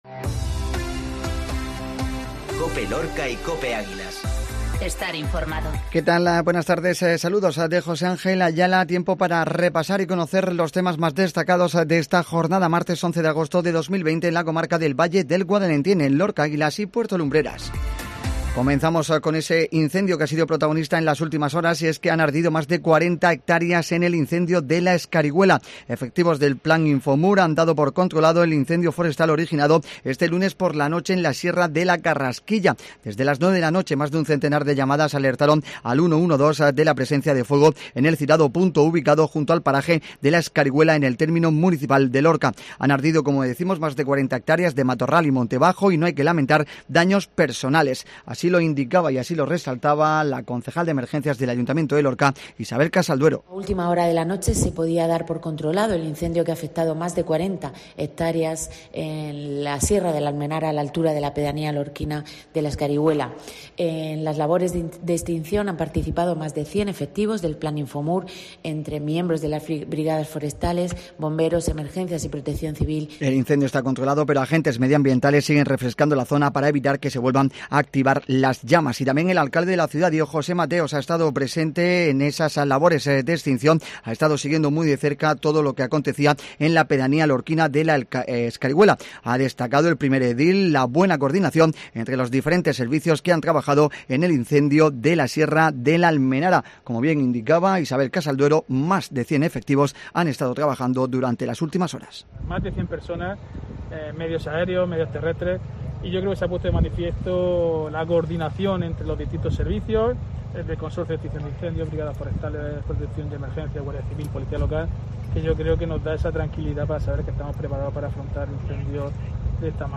INFORMATIVO MEDIODÍA COPE LORCA